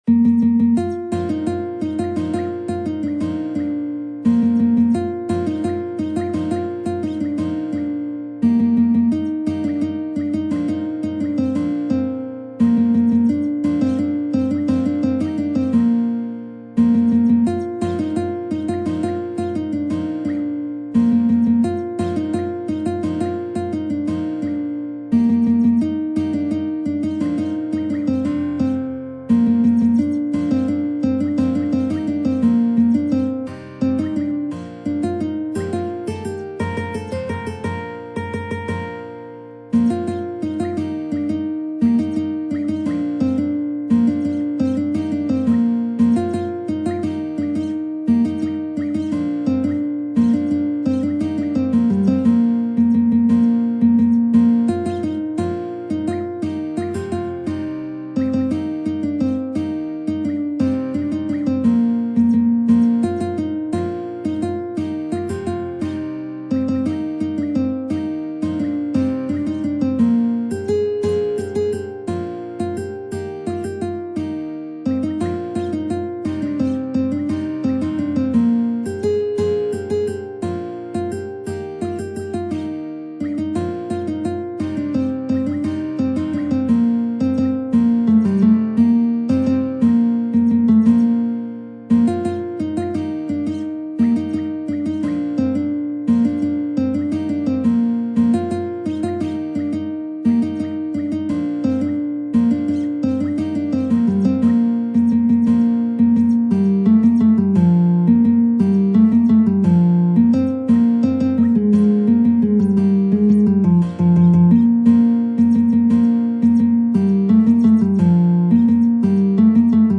نت ملودی به همراه تبلچر و آکورد